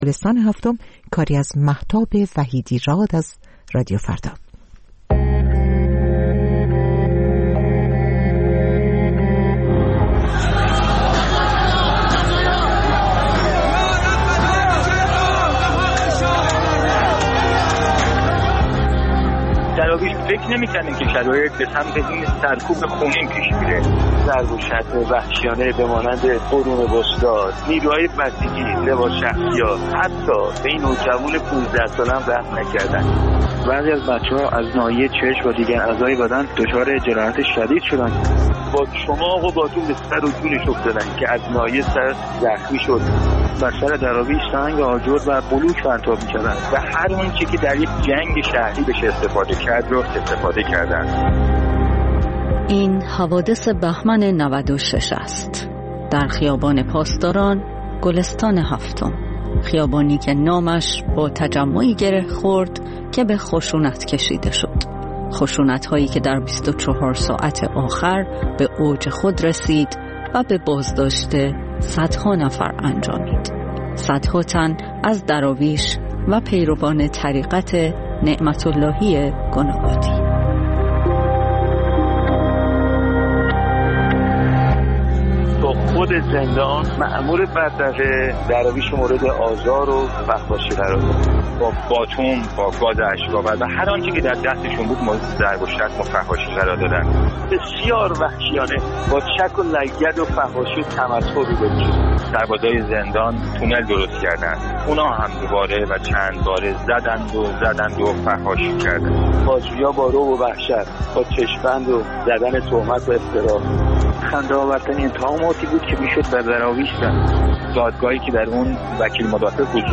بازپخش مستند رادیویی «گلستان هفتم»